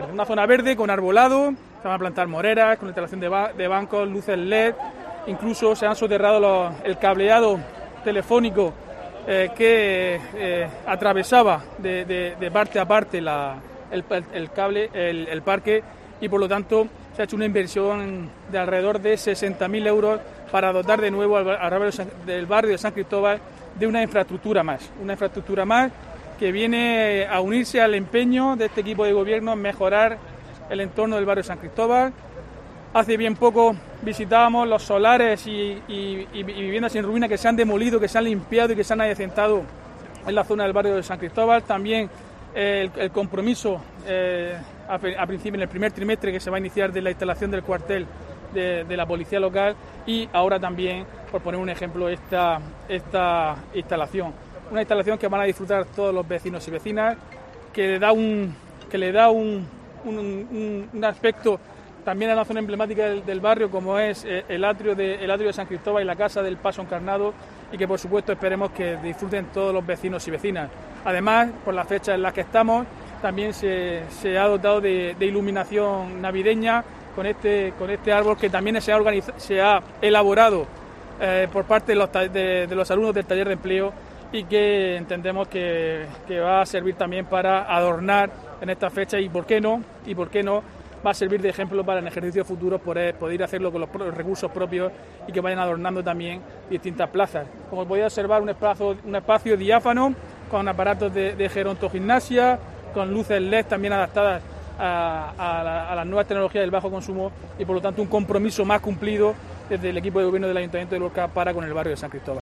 Diego José Mateos, alcalde de Lorca sobre obras plaza San Cristóbal